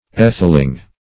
Atheling \Ath"el*ing\ ([a^]th"[e^]l*[i^]ng), n. [AS.